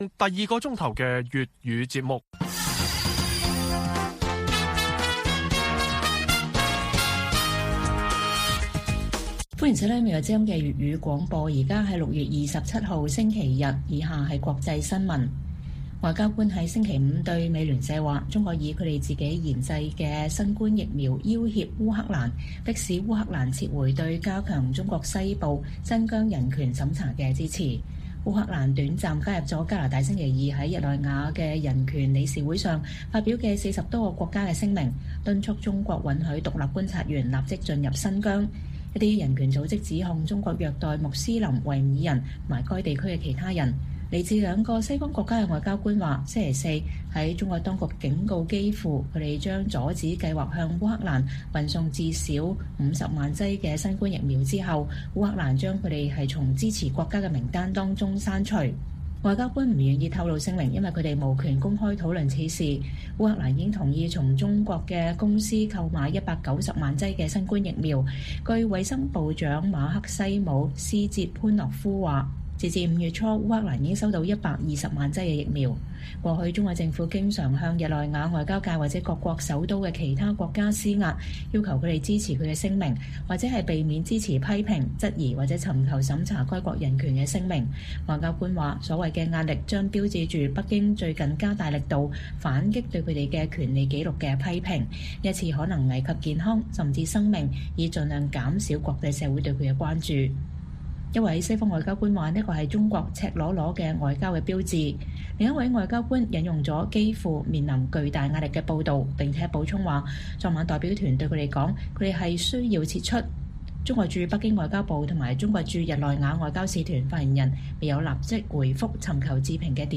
粵語新聞 晚上10-11點： 外交官：中國以疫苗要挾烏克蘭撤回對新疆人權審查的支持